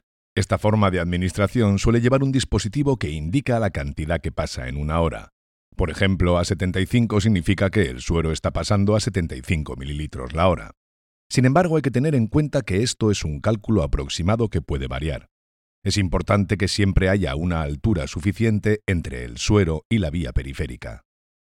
Kein Dialekt
Sprechprobe: eLearning (Muttersprache):
e-learning demo 3.mp3